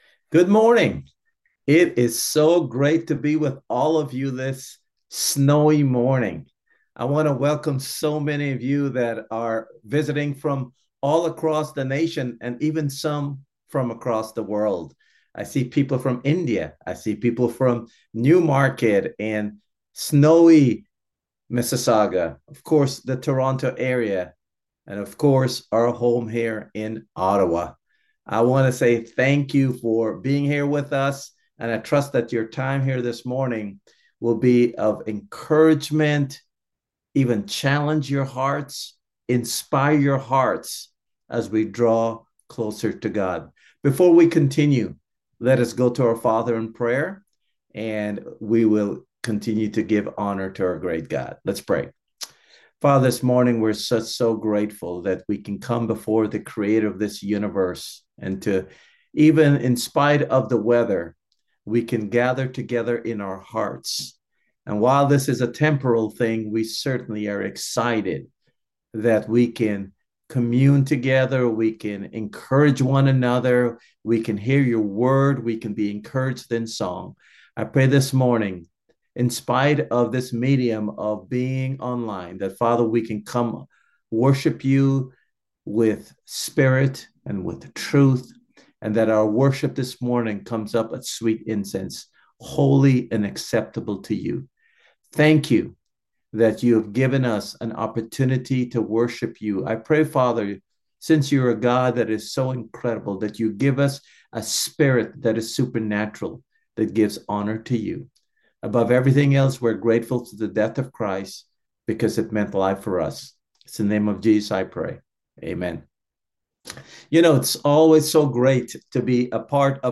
Podcast feed for sermons from Ottawa Church of Christ